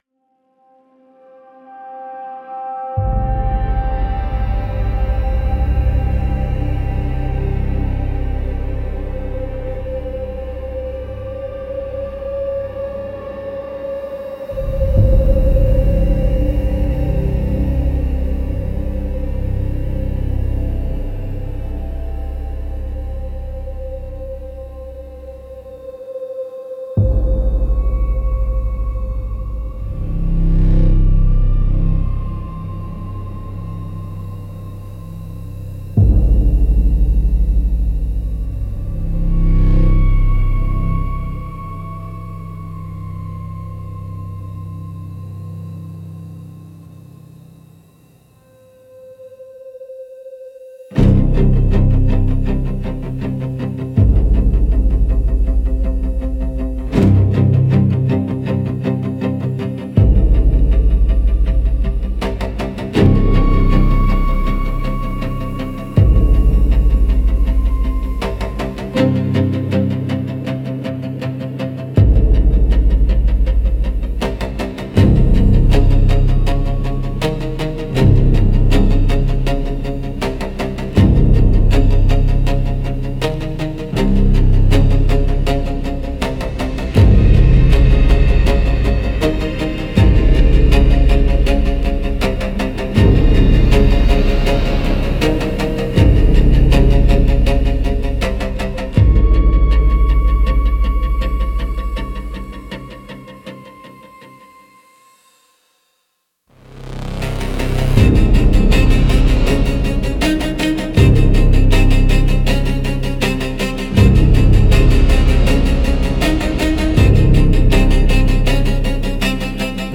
Creeping Suspense